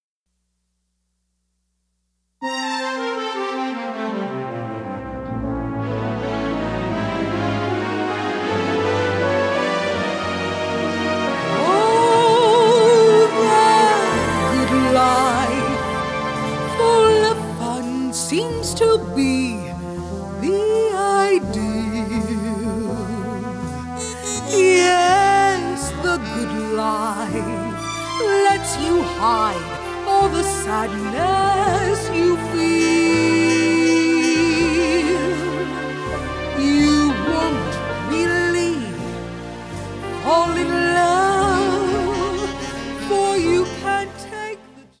Pno/Vocal
Female Voice